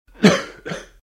cough5.wav